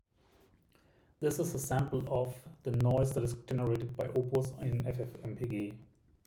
Cracks and pops when recording on Macbook
Microphone: AudioTecnica Condenser MIC
Shotcut: Cracks and pops both: Exported file and when listening the recorded clip.
ffmpeg -f avfoundation -i ":0" -c:a libopus -b:a 192k -application audio -ar 48000 -ac 2 -y output.opus